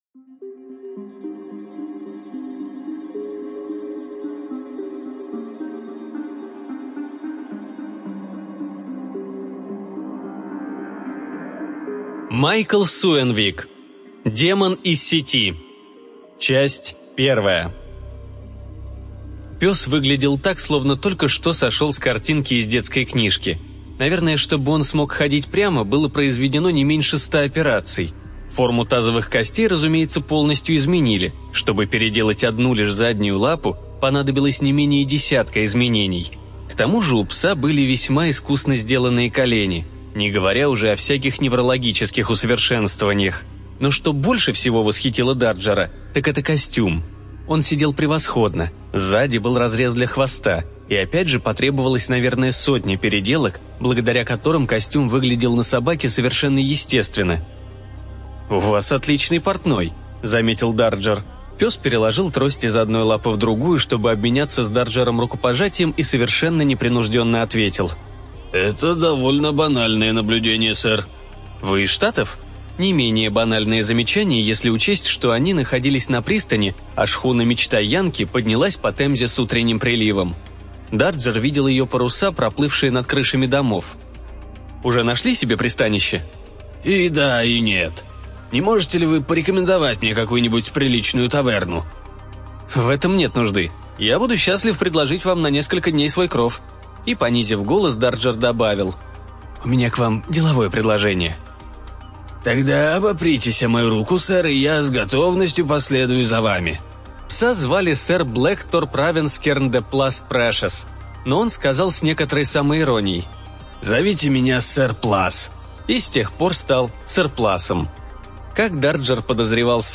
ЖанрФантастика